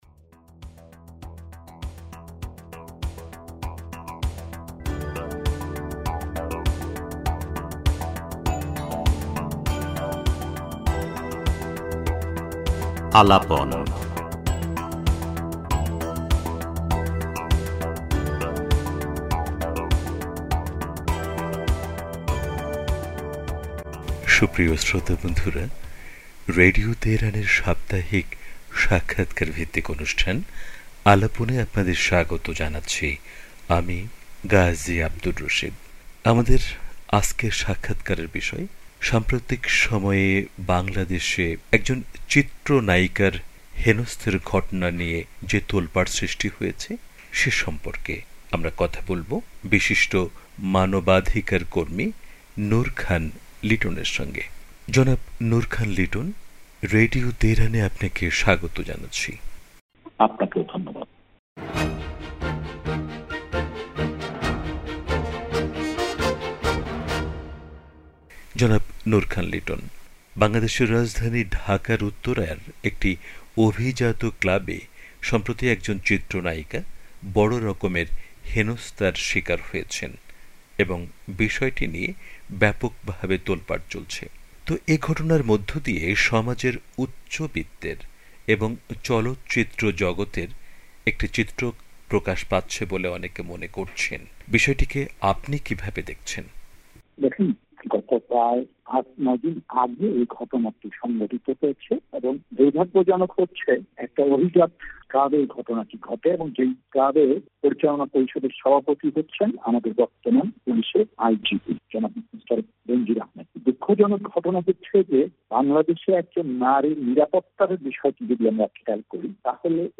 রেডিও তেহরানকে দেয়া বিশেষ সাক্ষাৎকারে